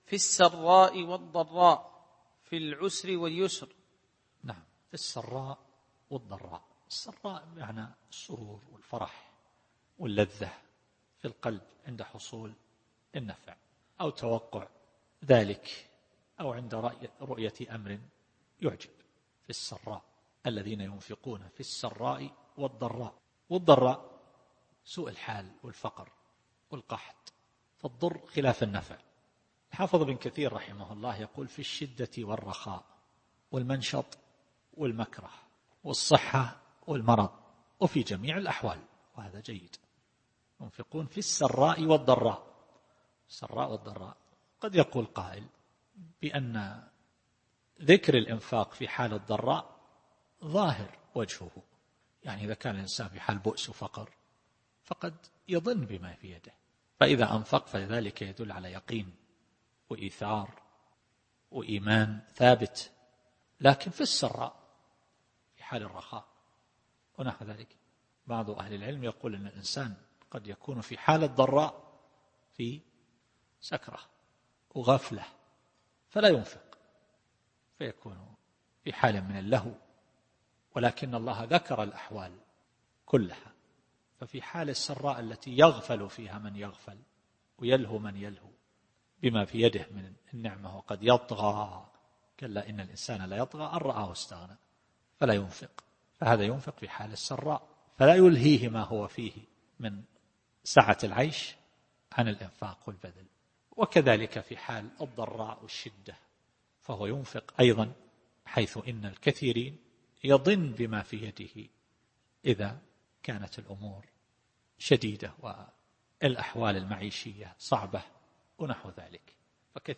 التفسير الصوتي [آل عمران / 134]